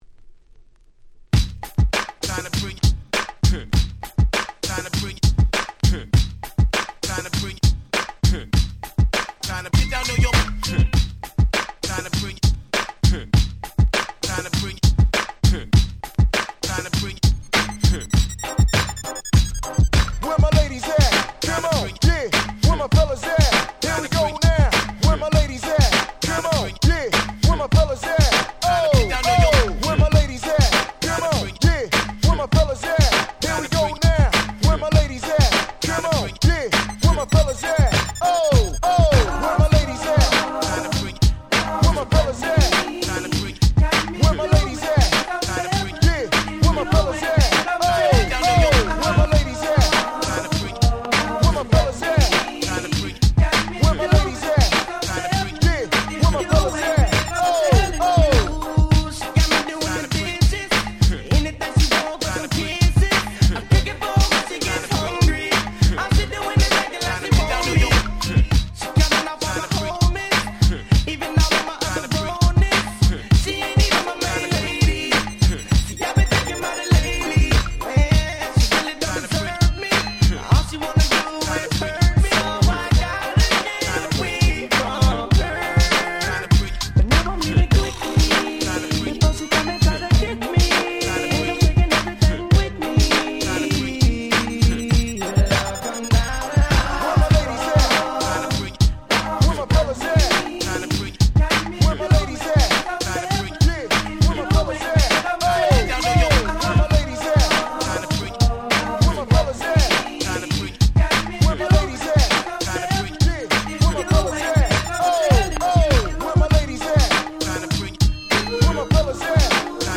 全6曲全部アゲアゲ！！